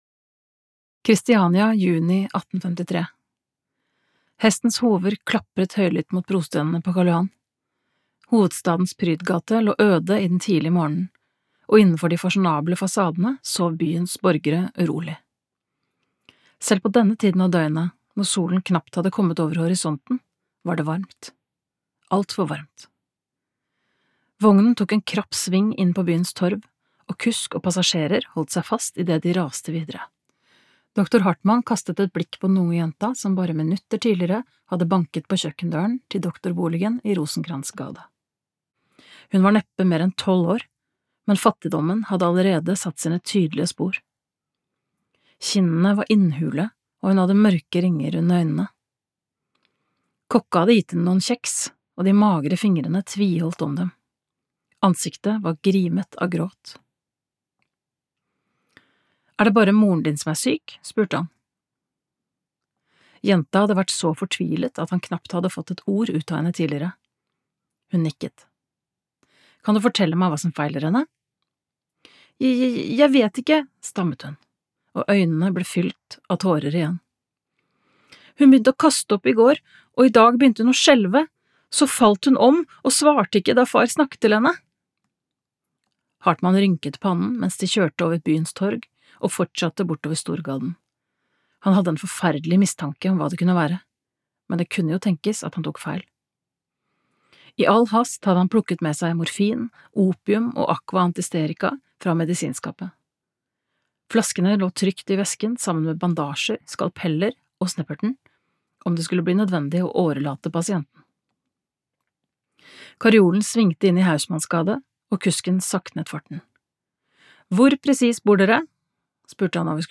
Utdraget er hentet fra lydbokbokutgaven